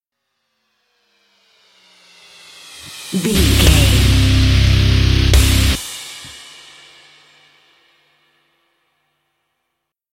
Aeolian/Minor
C#
drums
electric guitar
bass guitar
Sports Rock
hard rock
aggressive
energetic
intense
nu metal
alternative metal